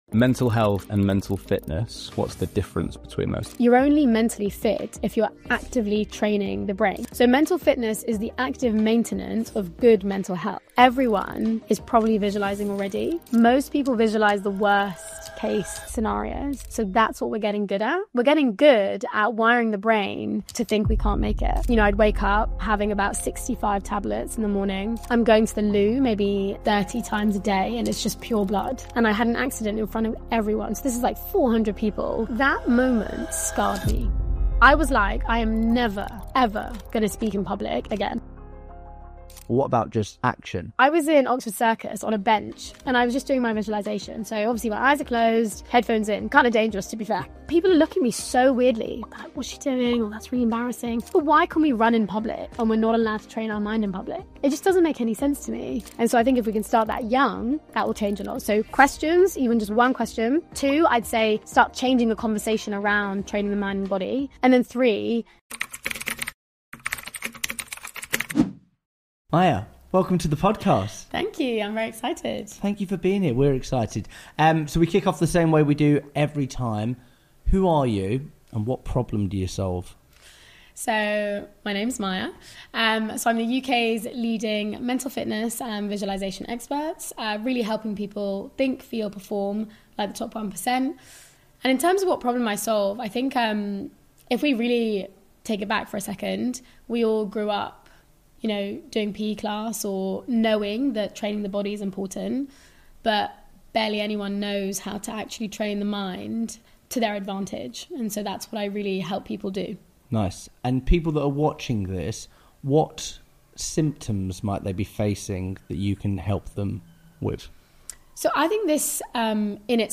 this podcast delivers unfiltered conversations with world-class entrepreneurs, thought leaders, and mental fitness coaches. From neuroscience-backed strategies to real-world sales tactics, every episode is designed to help you sell smarter, lead better, and live with purpose.